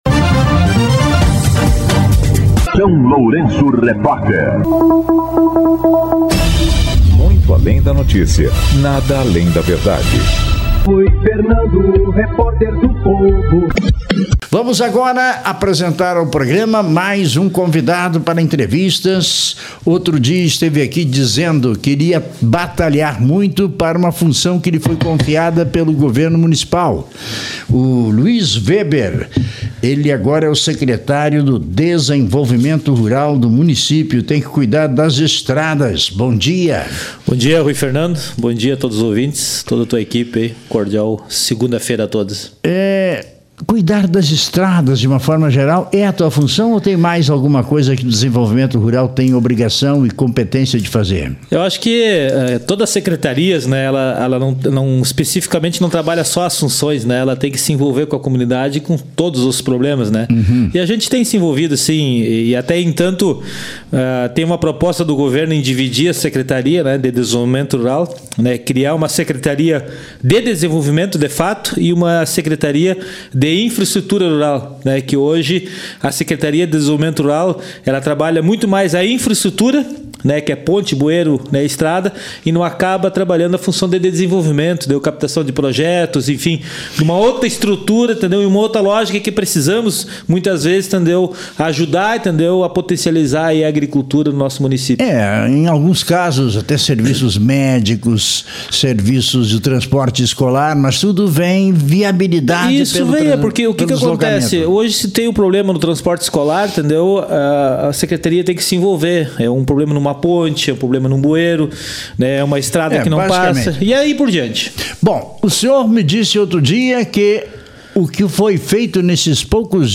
O Secretário do Desenvolvimento Rural de São Lourenço do Sul, Luis Weber, concedeu entrevista ao São Lourenço Repórter nesta segunda-feira (19), para detalhar o trabalho da pasta na recuperação das vias do interior, bem como pontes.